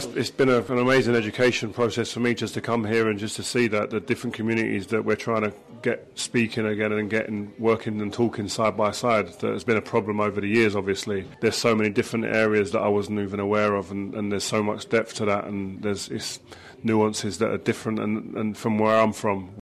At an event in Sligo this morning, the former Manchester United defender met people who travelled from the likes of Belfast, Omagh, Derry, and Donegal: